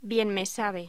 Locución: Bienmesabe